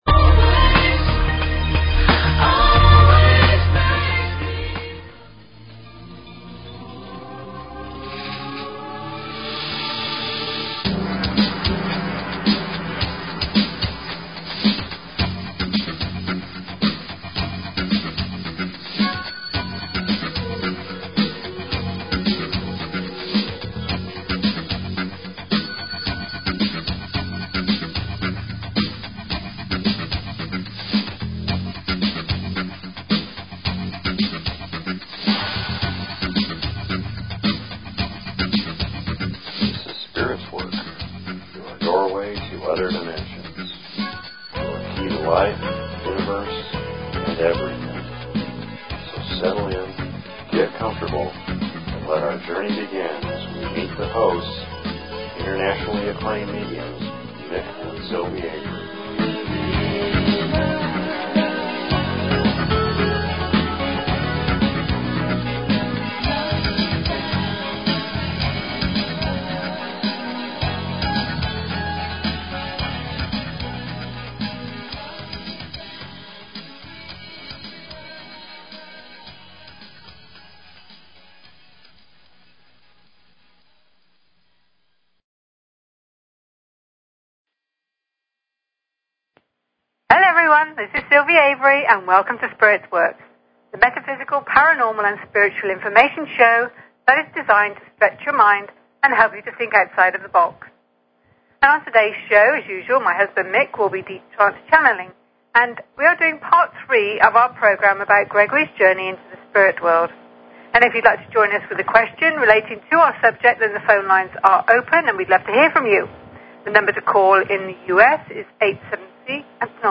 Talk Show Episode, Audio Podcast, Spirits_Work and Courtesy of BBS Radio on , show guests , about , categorized as